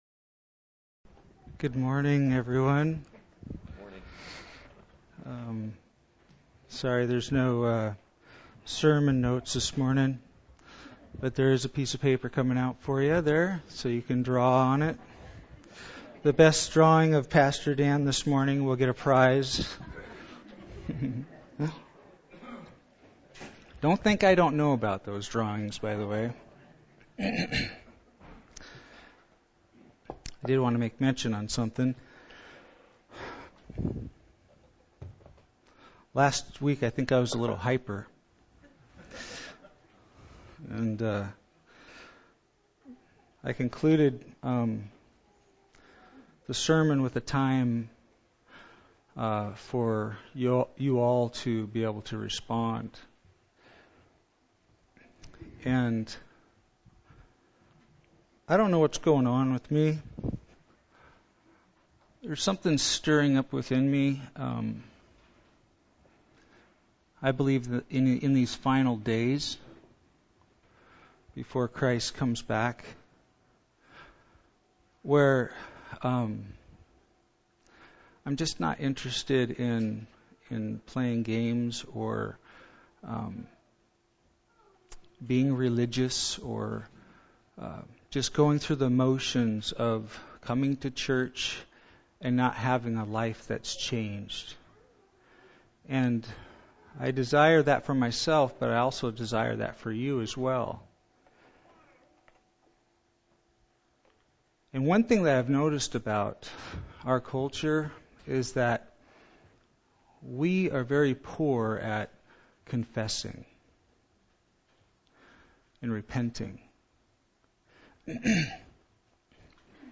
This sermon will challenge you to understand the Apostle John’s message the way the Seven Churches understood it.